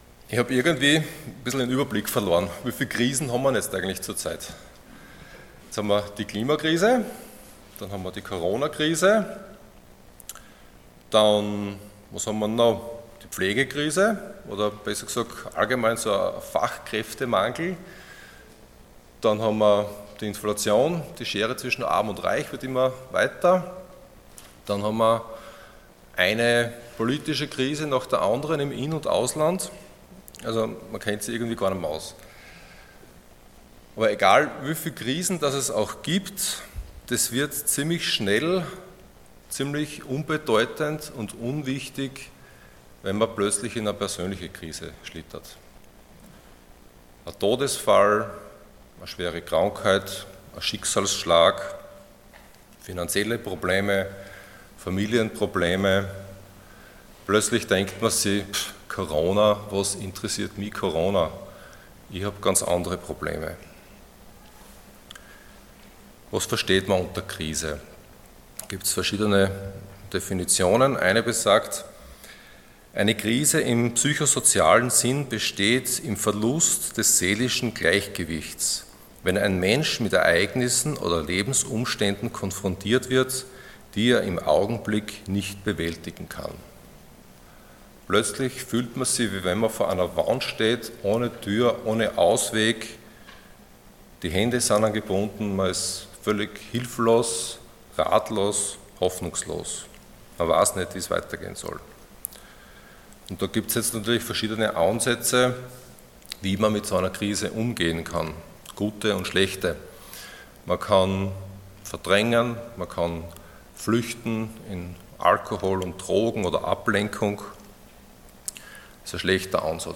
Passage: Psalm 1:1-6 Dienstart: Sonntag Morgen